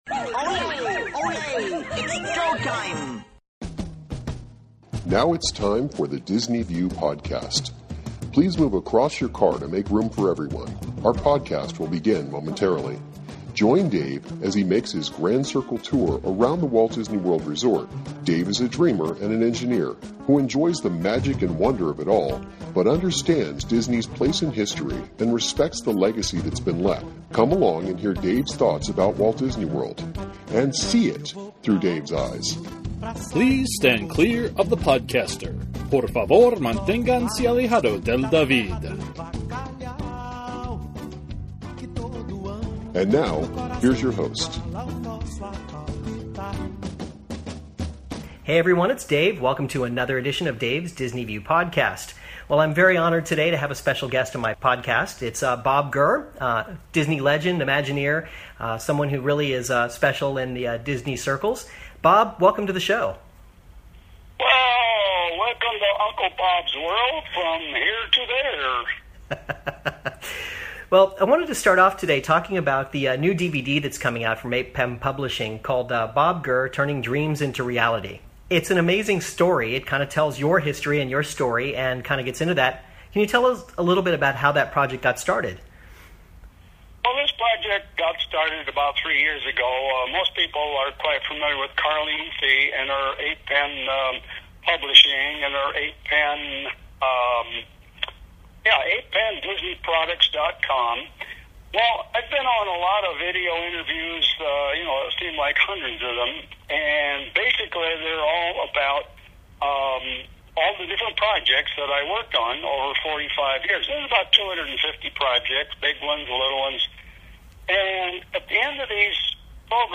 Disney Legend Bob Gurr joins me on today's show. We talk about his new DVD - Turning Dreams into Reality... he's got some great stories to tell.